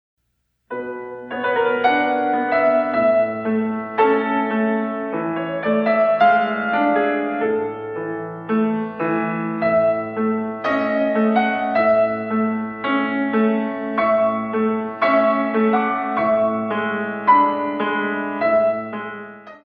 In 2
32 Counts